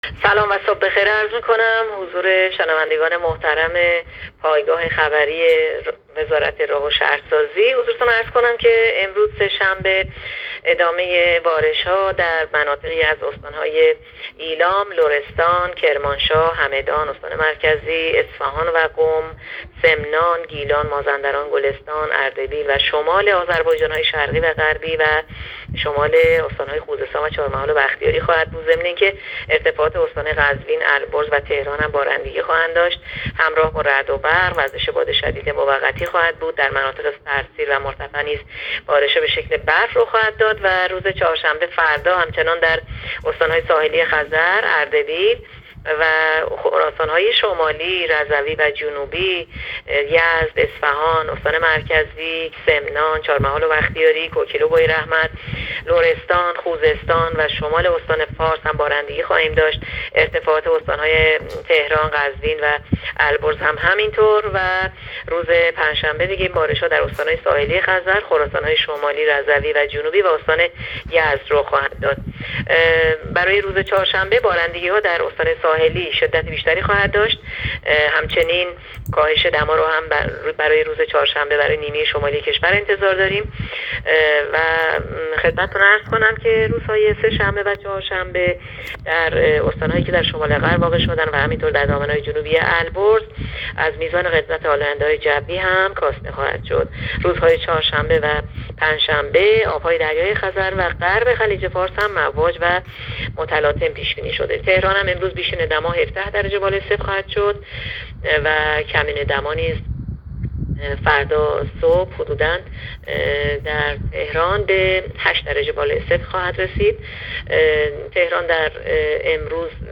گزارش آخرین وضعیت جوی کشور را از رادیو اینترنتی پایگاه خبری وزارت راه و شهرسازی بشنوید.
گزارش رادیو اینترنتی پایگاه‌ خبری از آخرین وضعیت آب‌وهوای ۱۱ آذر؛